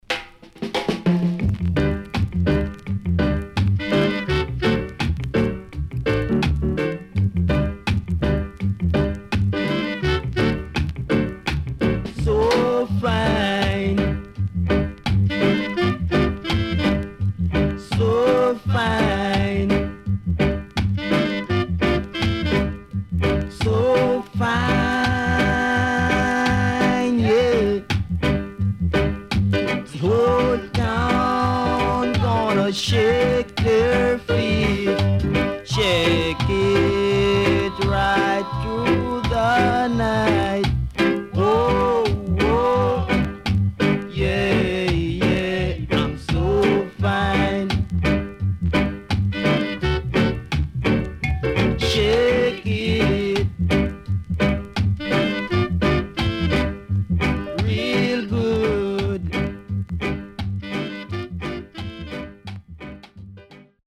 HOME > Back Order [VINTAGE 7inch]  >  ROCKSTEADY
68年 Nice Rocksteady
SIDE A:所々チリノイズがあり、少しパチノイズ入ります。